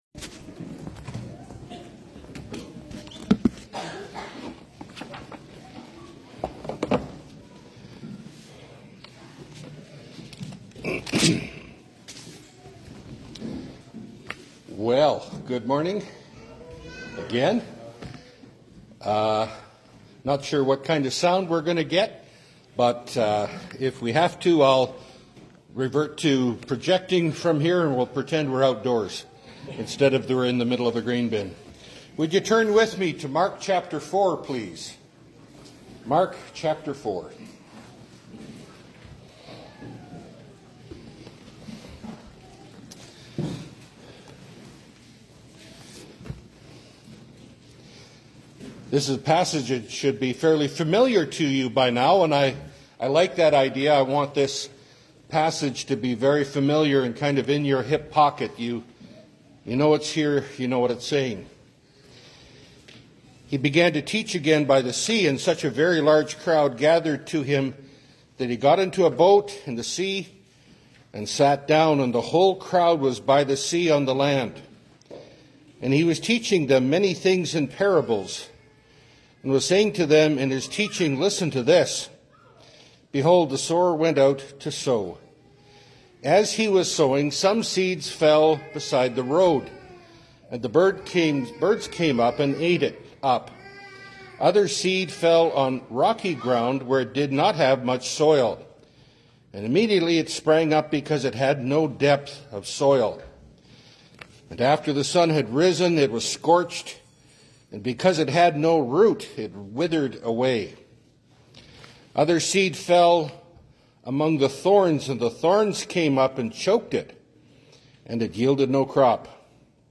Pulpit Sermons